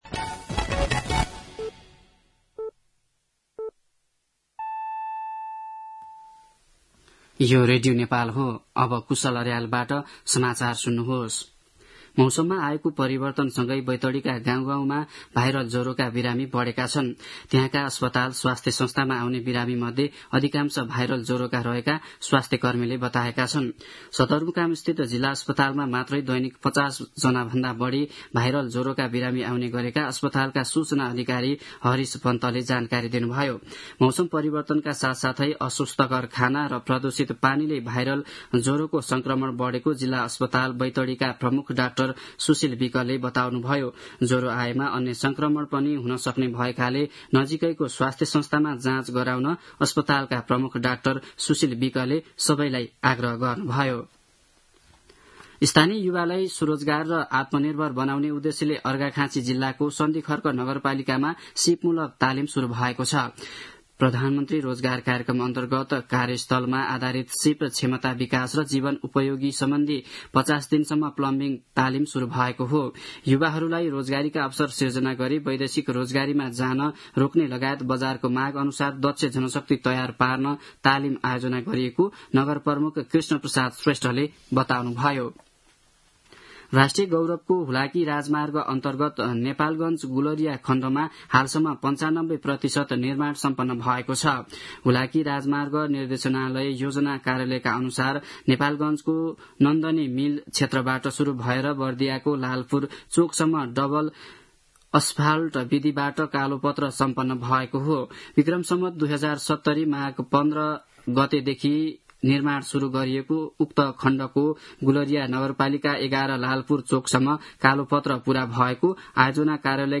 दिउँसो ४ बजेको नेपाली समाचार : ६ चैत , २०८१
4-pm-News-12-06.mp3